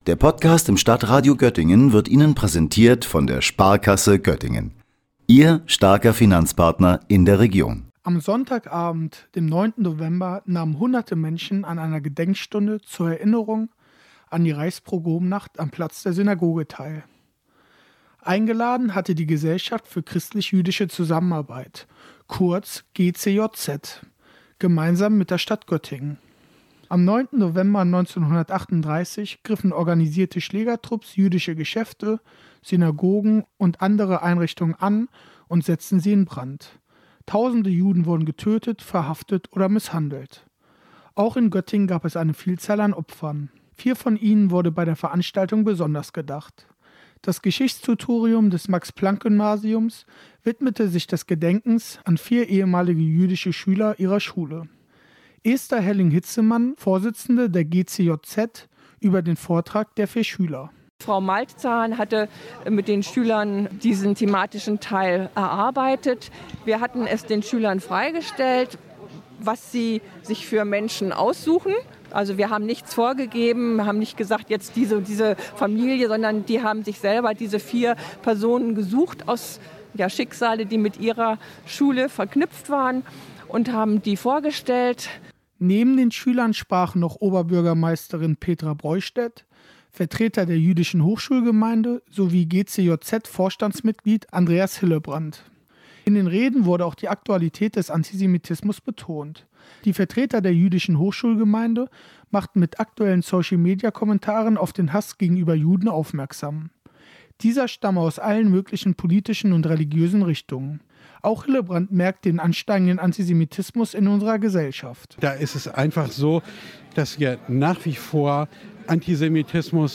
Beiträge > Erinnern an das dunkelste kapitel deutscher Geschichte - Gedenkveranstaltung zur Reichspogromnacht in Göttingen - StadtRadio Göttingen
Am 9. November 1938 ermordeten die Nazis tausende Juden, plünderten ihre Geschäfte und steckten Synagogen in Brand. In Göttingen fand gestern am Platz der Synagoge eine Gedenkfeier statt.